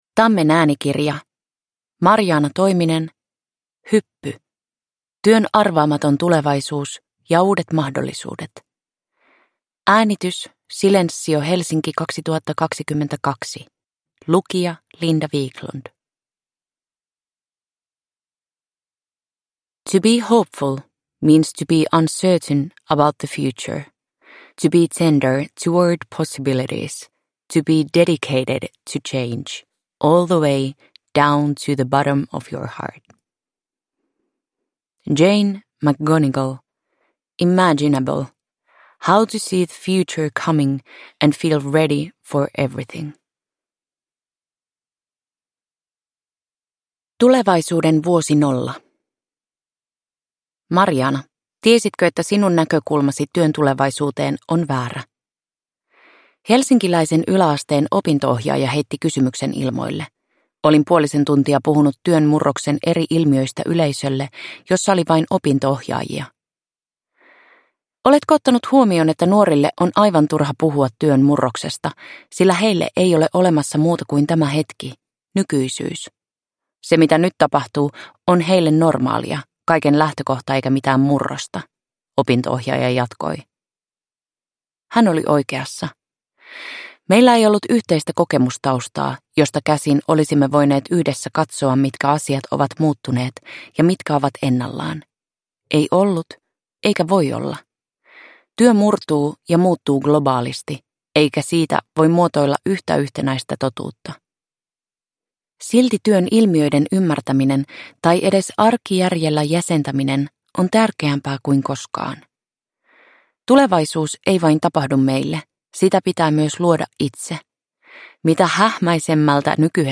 Hyppy – Ljudbok – Laddas ner